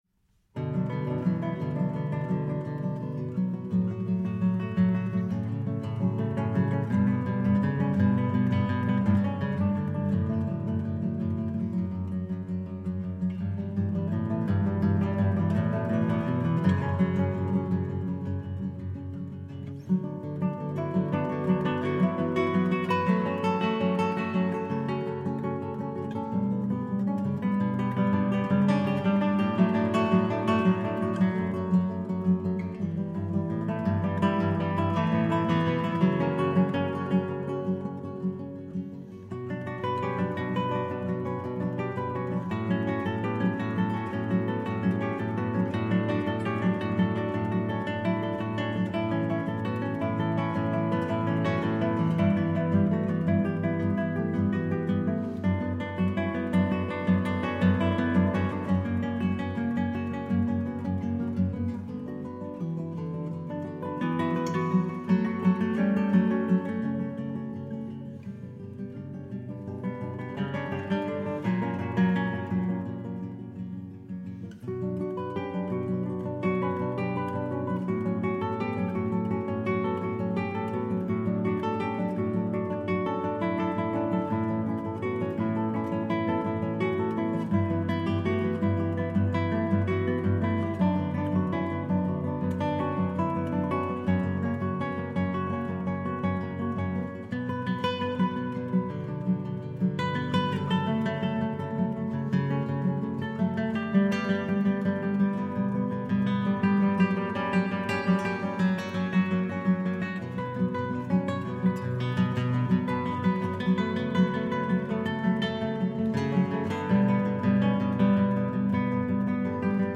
Work for guitar duo
Genre chamber work
Instrumentation guitar duo
Musical styles and elements modal, melodic
Shimmering lights that gather in circles